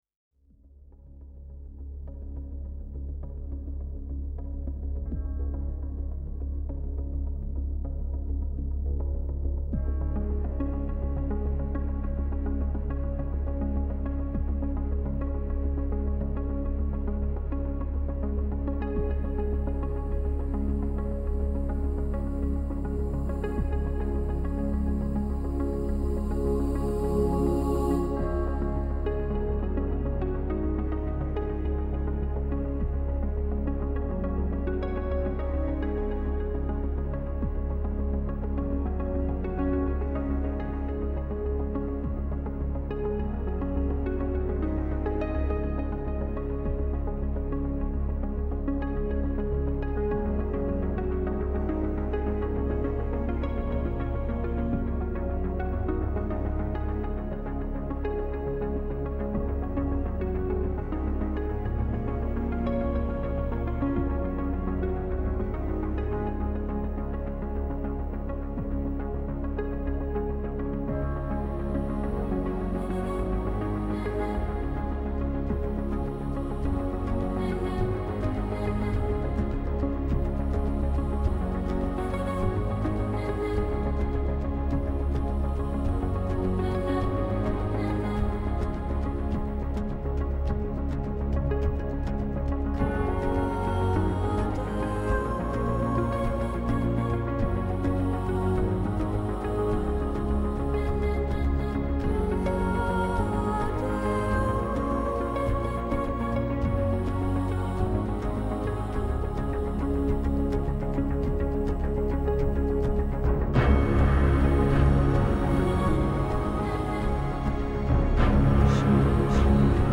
New age Нью эйдж